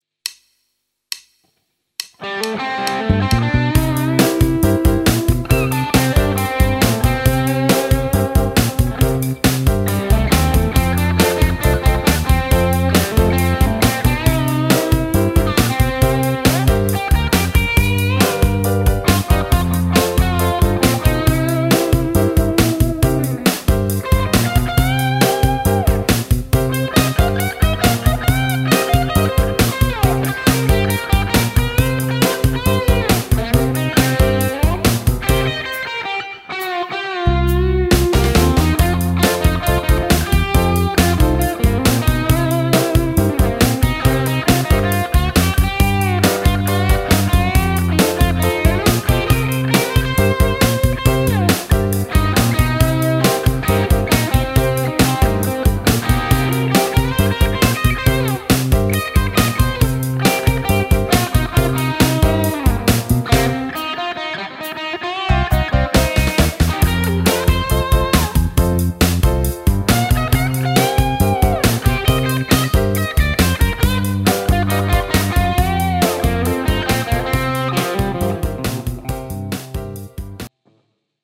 Kelpo soittoa, hyvin se sinne taustaan istuu, hyvä meininki.
Kivoja venytyksiä ja vibraatoa.
hienoa gaikua ja kuvioita 2 p